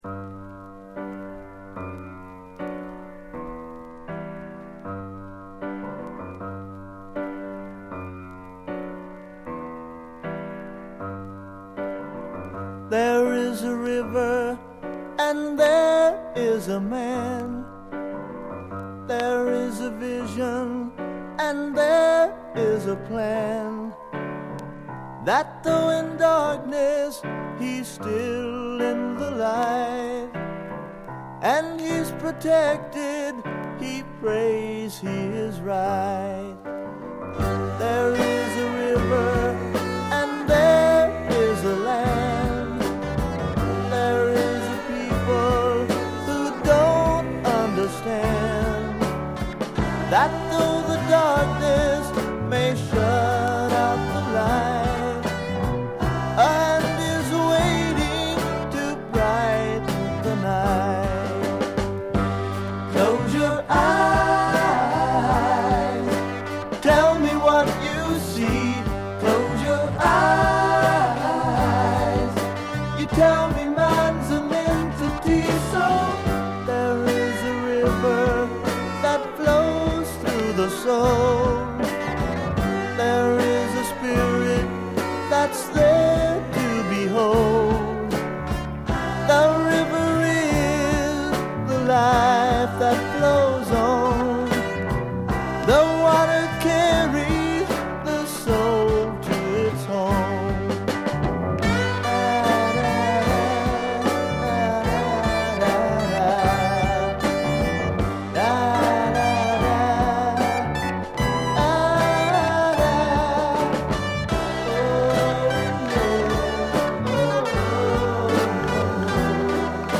Just great guitar throughout and snappy drums too.